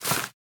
1.21.5 / assets / minecraft / sounds / block / vine / climb3.ogg
climb3.ogg